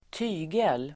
Uttal: [²t'y:gel]